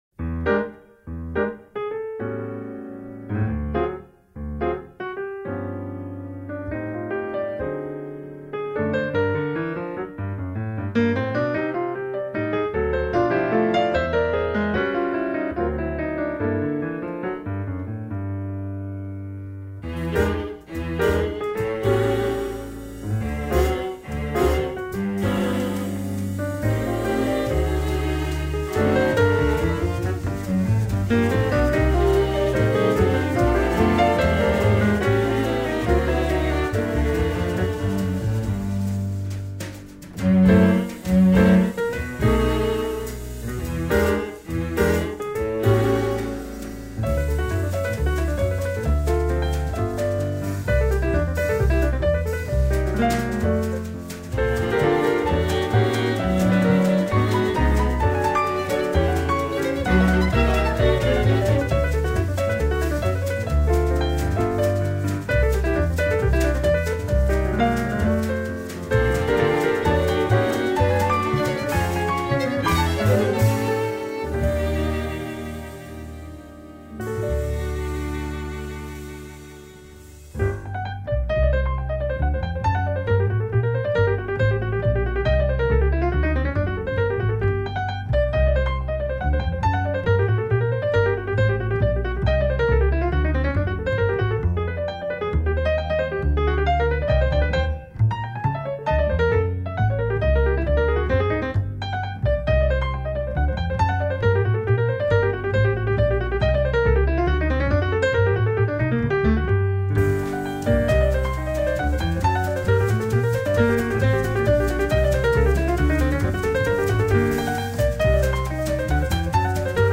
La fusion si difficile d'un ensemble à cordes
et d'un trio jazz est ici pleinement réussie car la qualité
piano
contrebasse
batterie.
violons
alto
violoncelle